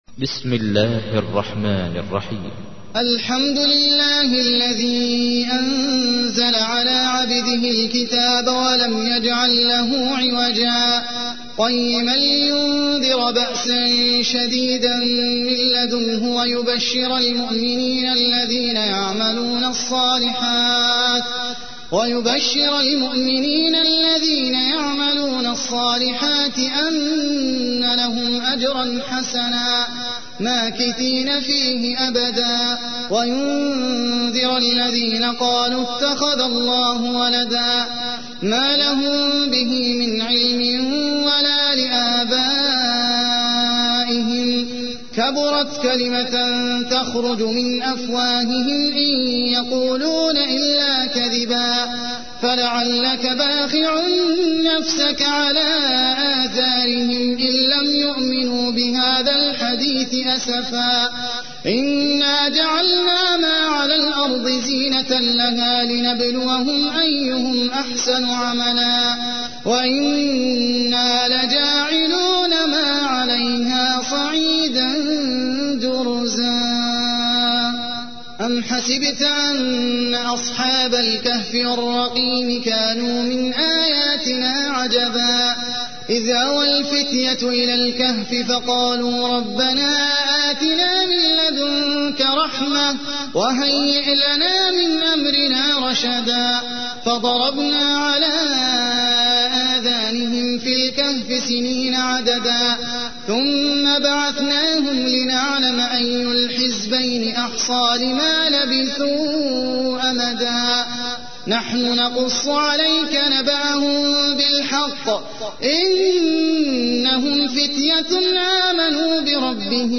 تحميل : 18. سورة الكهف / القارئ احمد العجمي / القرآن الكريم / موقع يا حسين